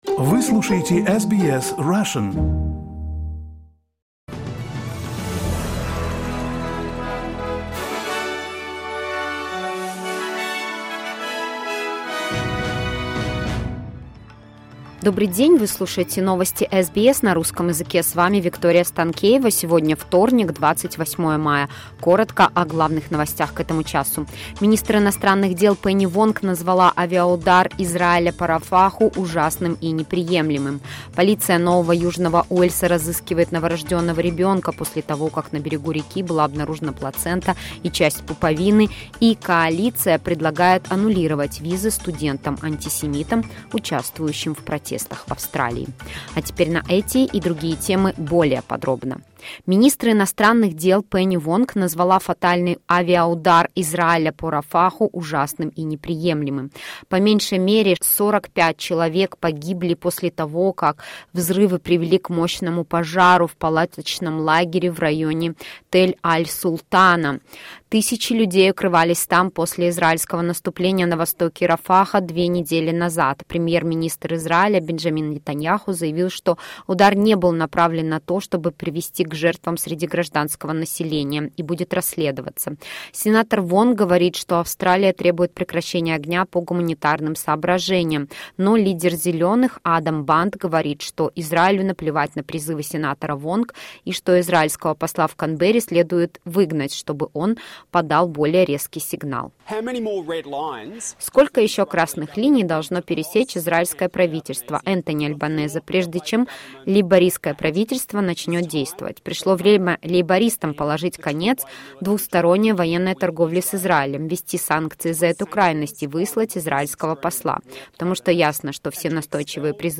SBS News in Russian — 28.05.2024